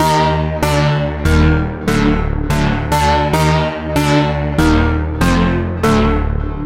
Tag: 144 bpm Trap Loops Synth Loops 1.12 MB wav Key : Unknown